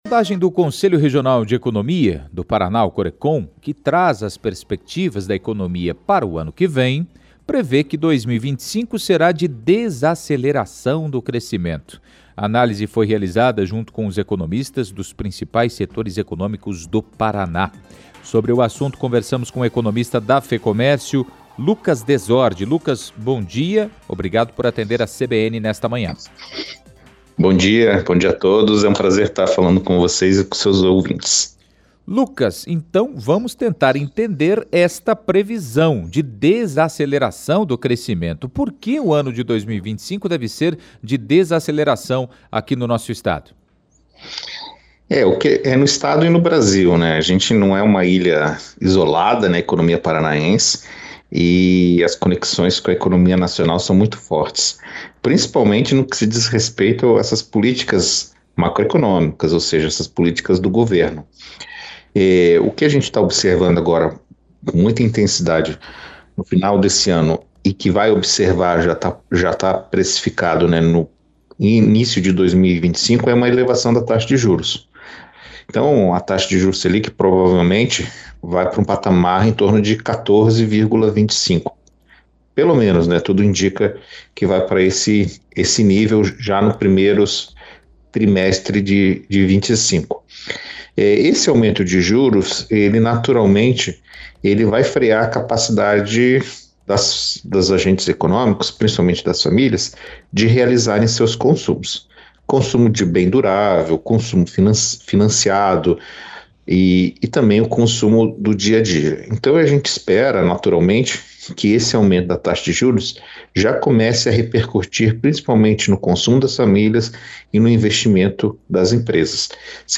2025 deve ser de desaceleração da economia paranaense – CBN Curitiba – A Rádio Que Toca Notícia